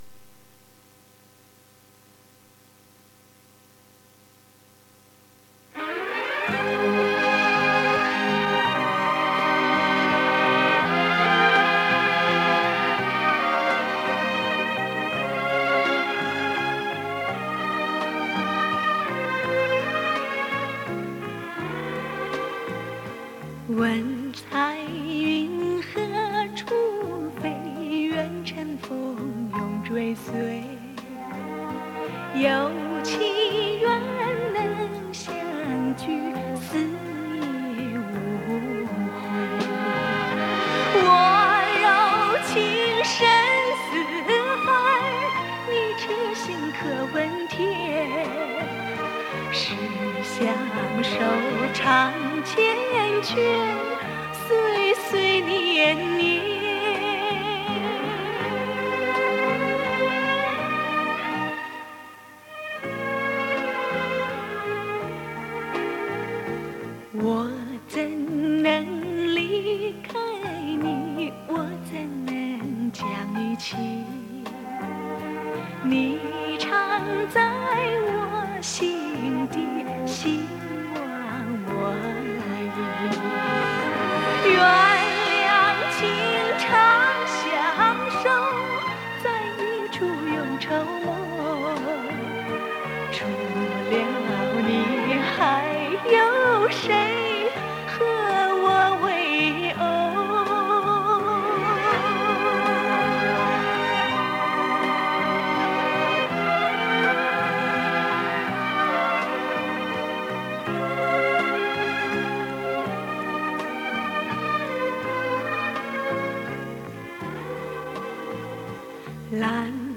磁带数字化：2022-08-26